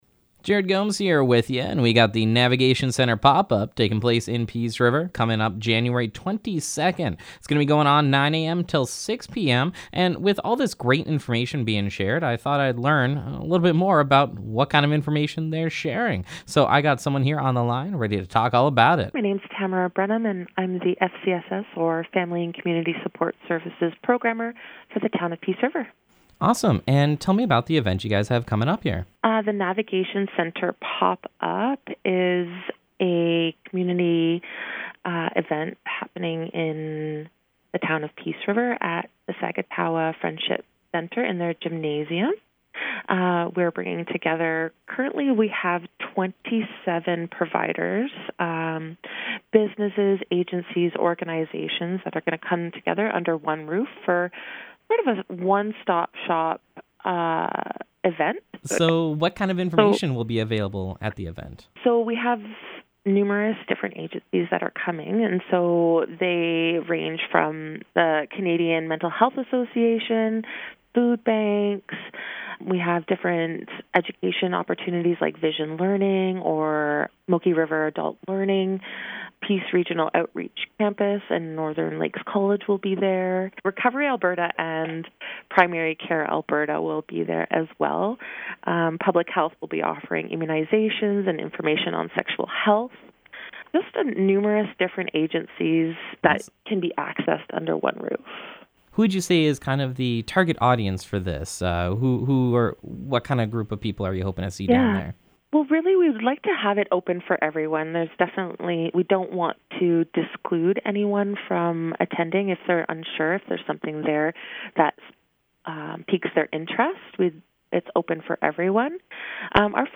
Navigation Centre Pop-Up Interview
navigation-center-pop-up-interview.mp3